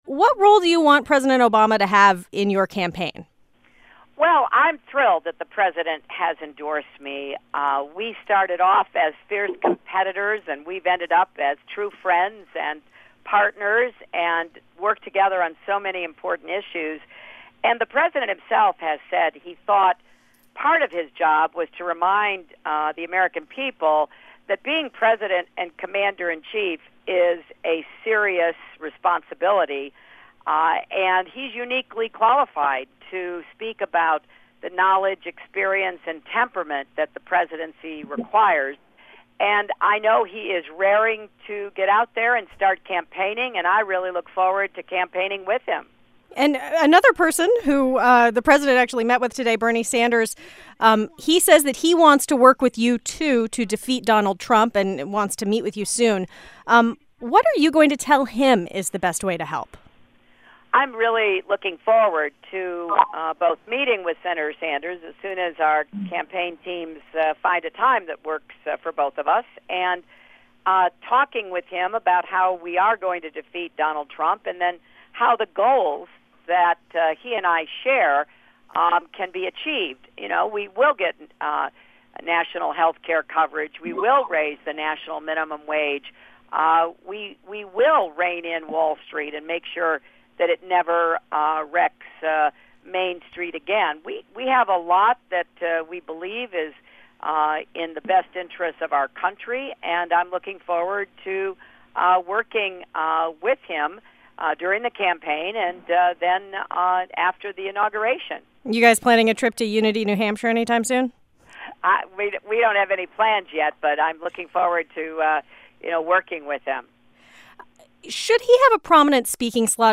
Hillary Clinton Speaks To NPR About President Obama's Endorsement